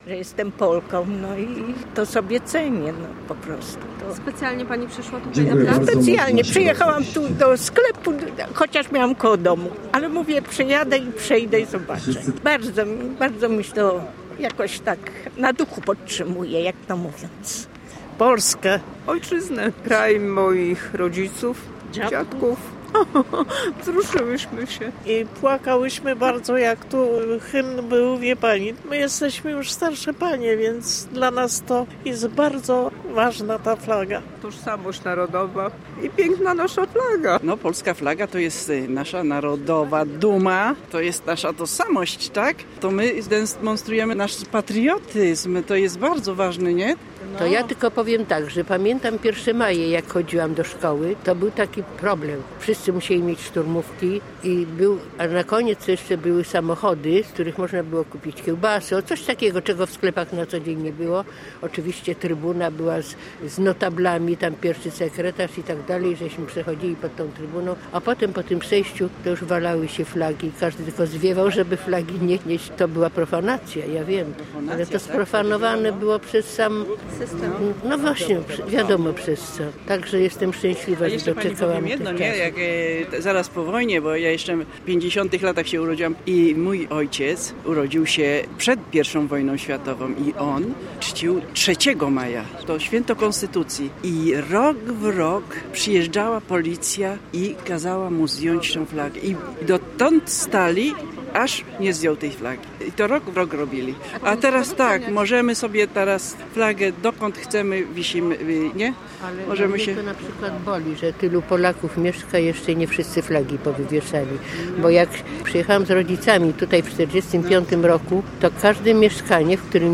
W Słupsku odbyły się one punktualnie o godzinie 12.